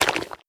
slime9.wav